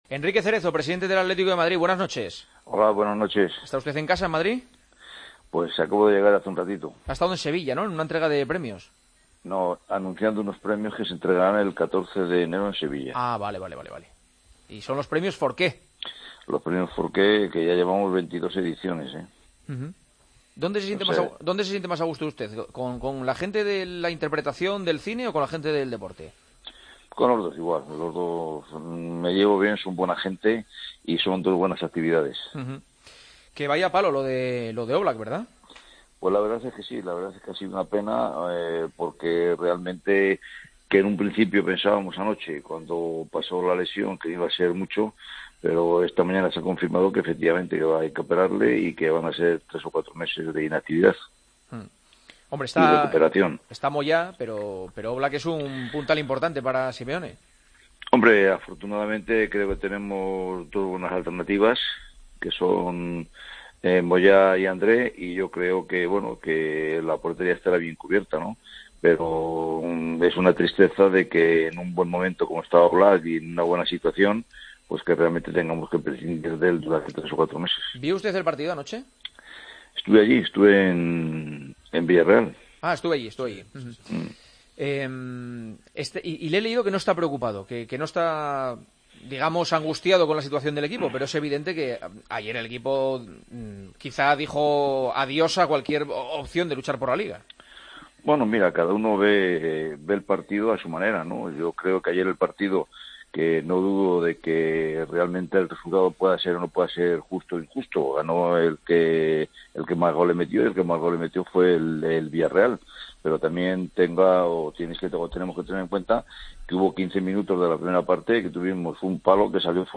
AUDIO | Entrevista a Enrique Cerezo, en El Partidazo de COPE